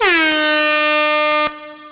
FX [ Horn ].wav